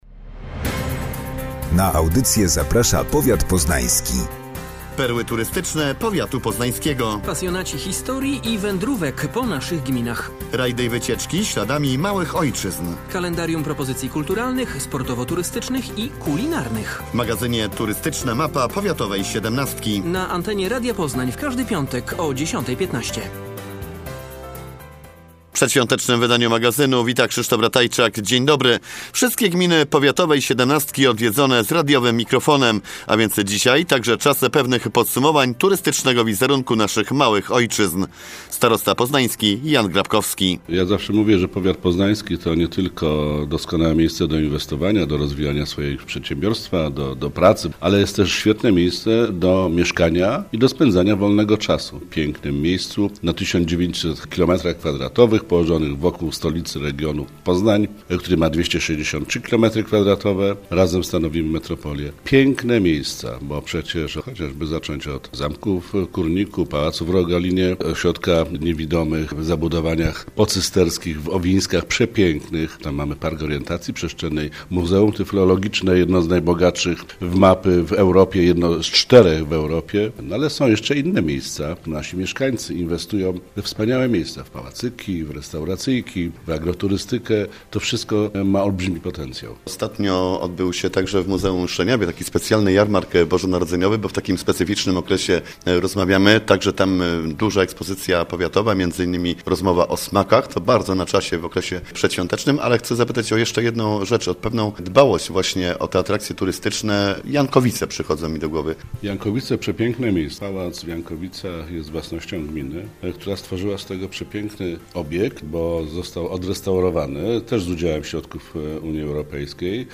O atrakcjach turystycznych rozmawiamy zatem ze starostą poznańskim, Janem Grabkowskim. W programie, tradycyjnie nie brakuje również kalendarium wydarzeń nadchodzącego weekendu.